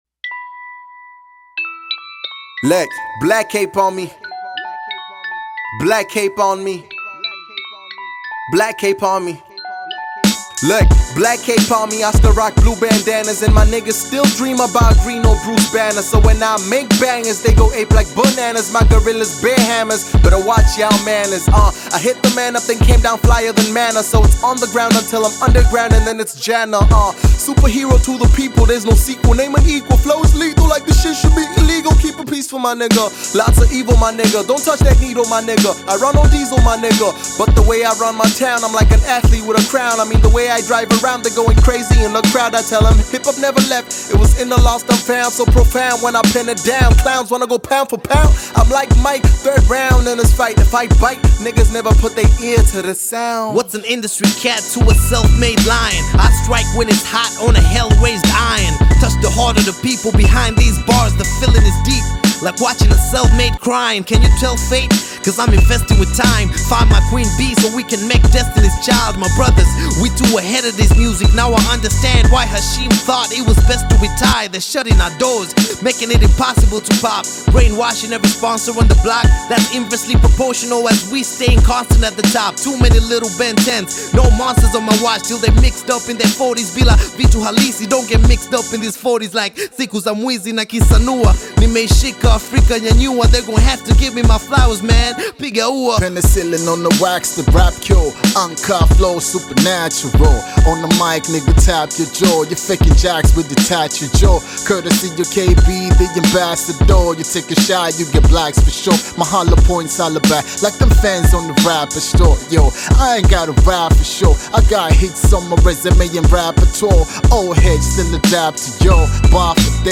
Bongo Flava
cypher song
African Music